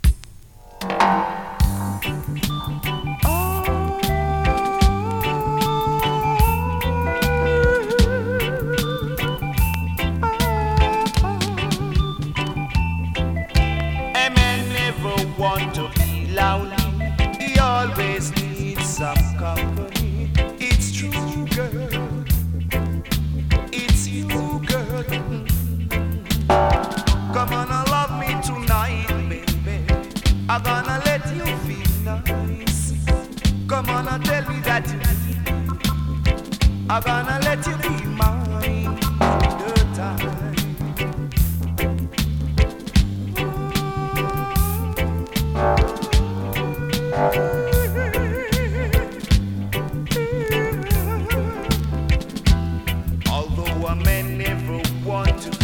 2022 NEW IN!! DANCEHALL!!
スリキズ、ノイズかなり少なめの